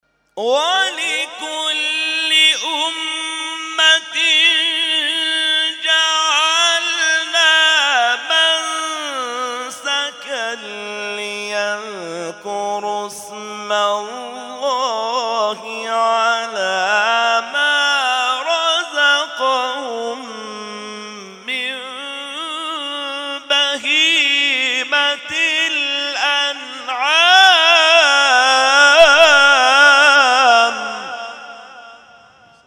محفل انس با قرآن در آستان عبدالعظیم(ع)+ صوت